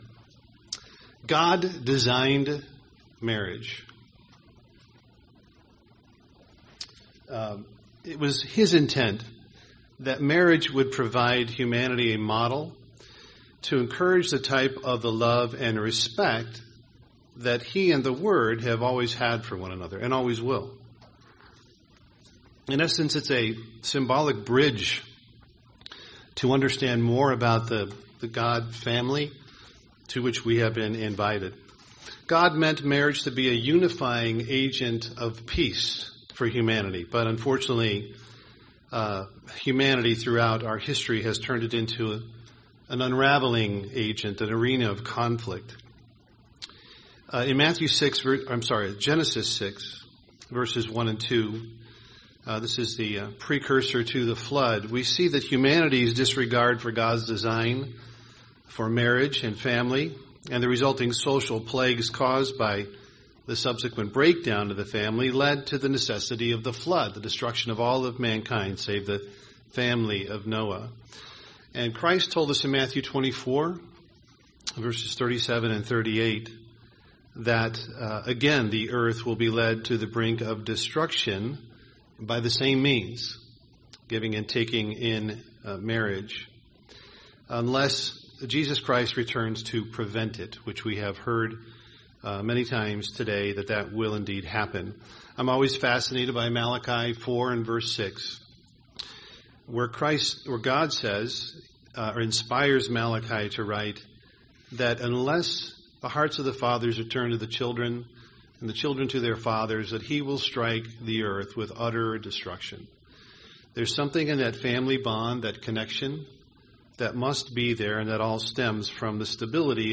UCG Sermon Feast of Trumpets marriage covenant Studying the bible?